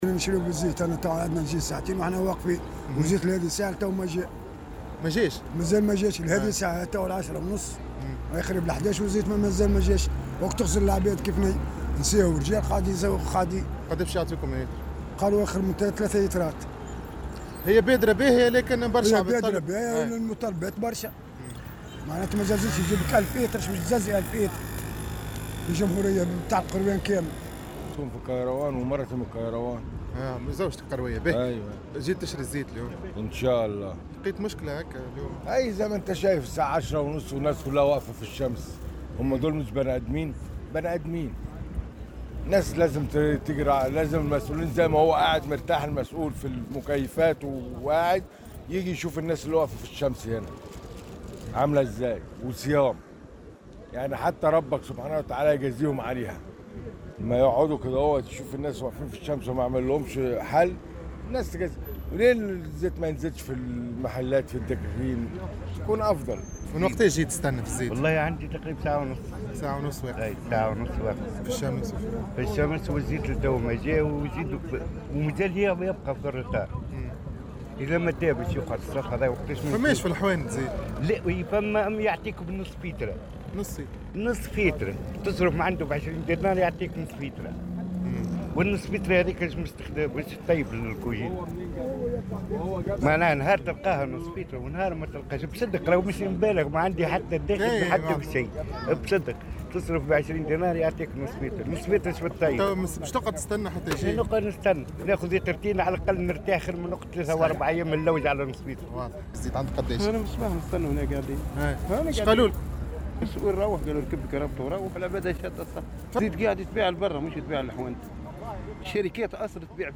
وعبّروا في تصريحات لمراسل "الجوهرة أف أم" عن تذمّرهم من نقص هذه المادة في الأسواق والبيع المشروط، مطالبين السلط المعنية بالتدخل وتوفير المواد المدعمة خاصة التي يكثر استهلاكها خلال شهر رمضان.